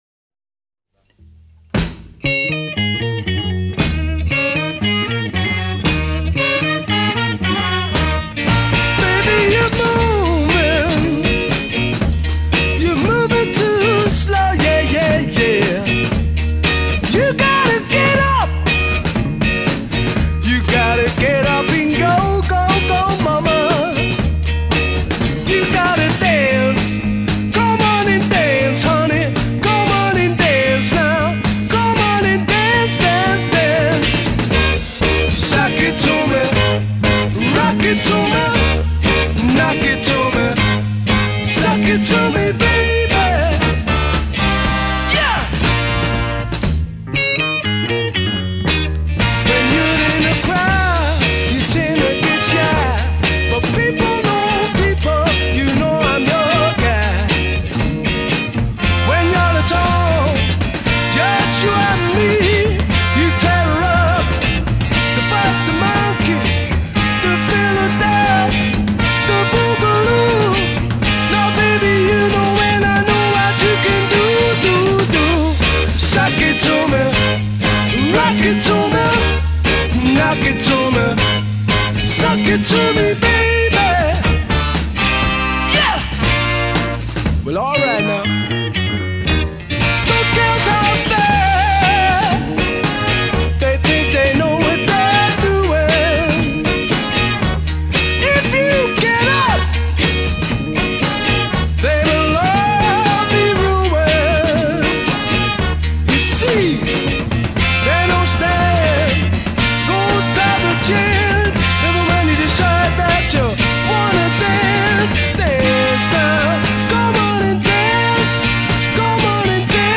EMI Studios, Abbey Road, London